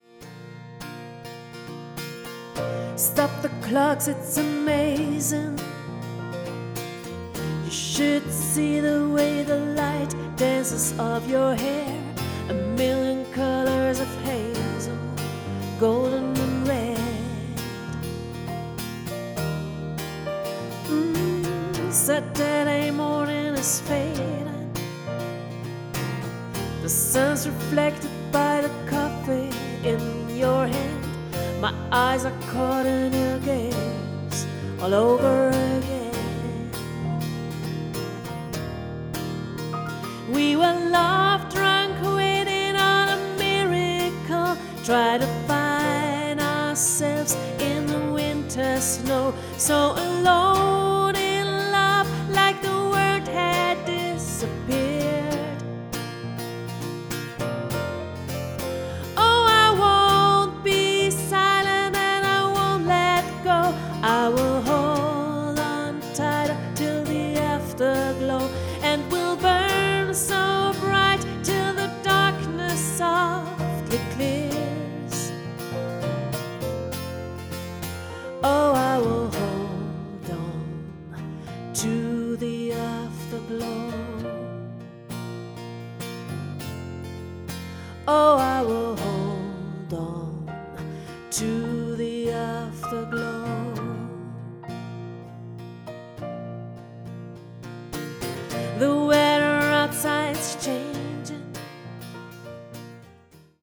Demos Acoustic / Easy Listening: